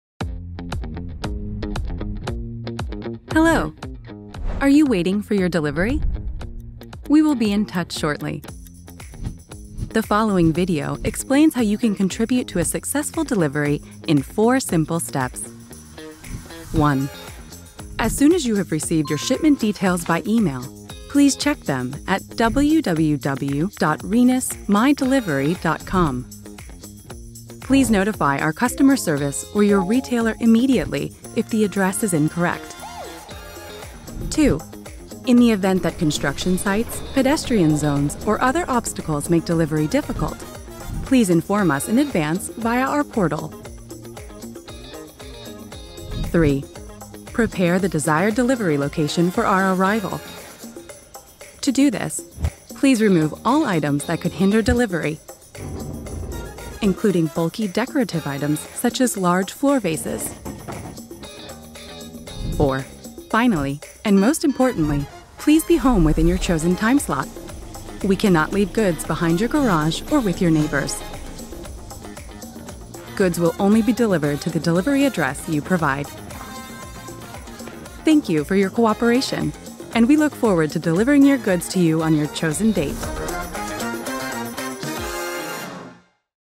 Voice
Young, Cool, Versatile, Reliable, Warm
Corporate
Explainer